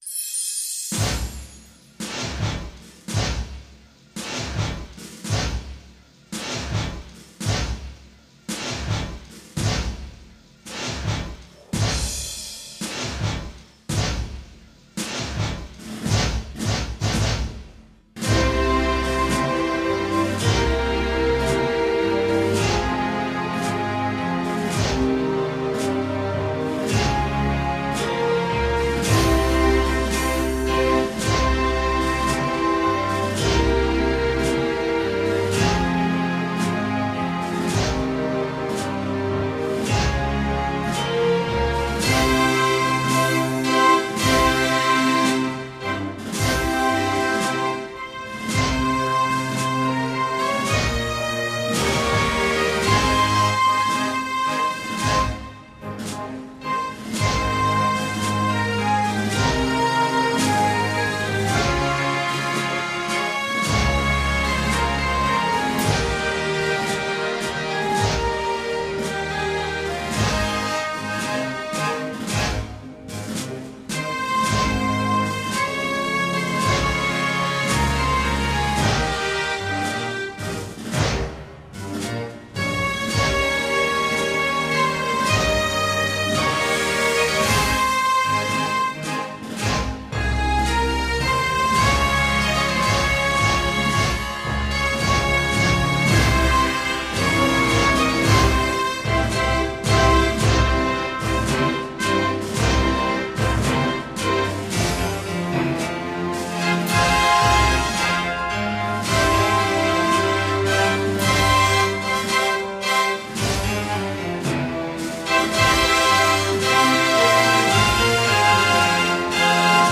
Instrumentación recogida in situ y modificada digitalmente.